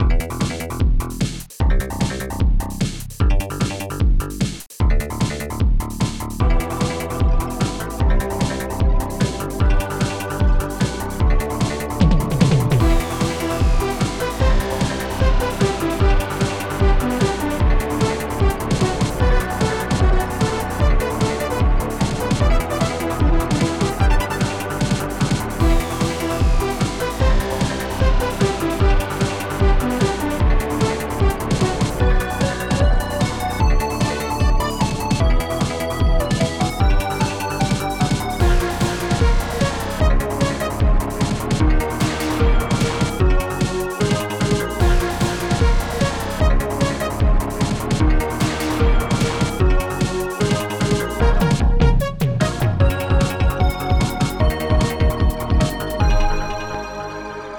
s3m (Scream Tracker 3)
a funny tune.